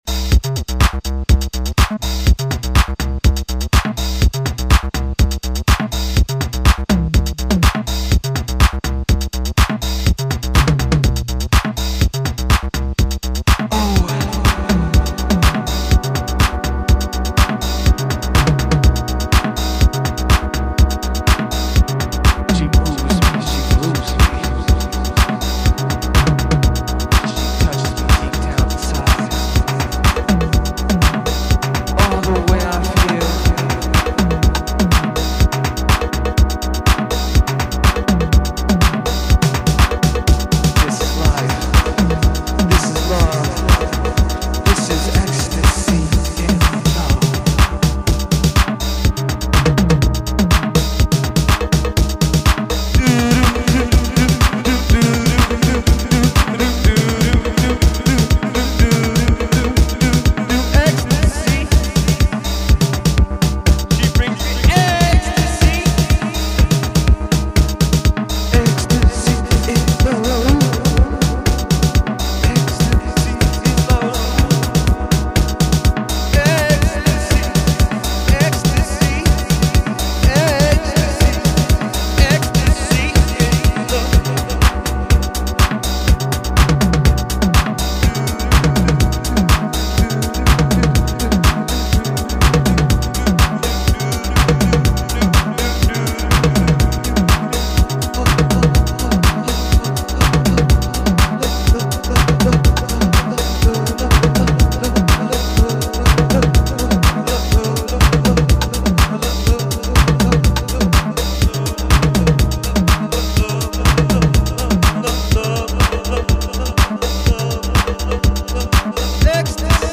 House Chicago